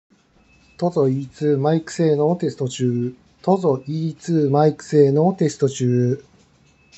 マイク性能は平均ぐらい
✅「TOZO E2」マイク性能
こもっていたり聴きにくかったりがあまりない。